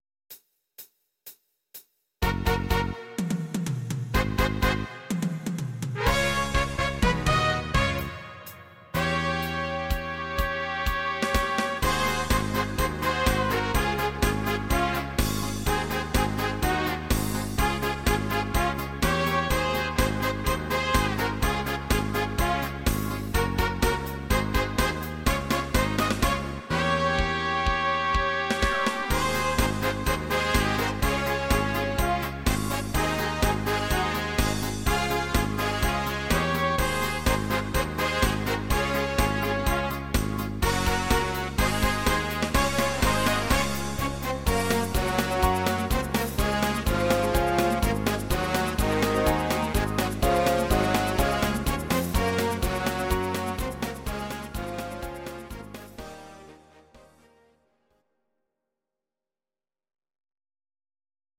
These are MP3 versions of our MIDI file catalogue.
Please note: no vocals and no karaoke included.
Orchester